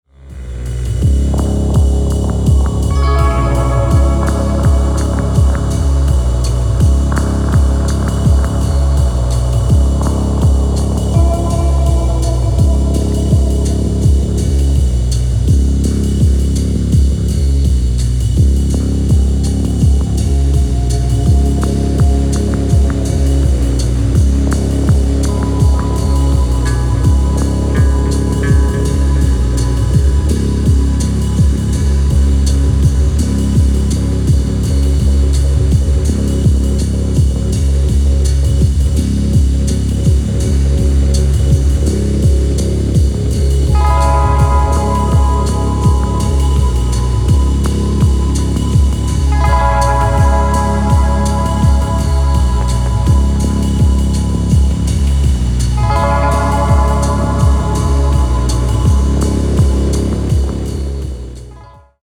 epic yet somehow erotic omnibus of slow-motion electronica
flirtatious melancholia